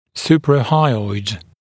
[ˌsuprə’haɪɔɪd][ˌсупрэ’хайойд]надподъязычный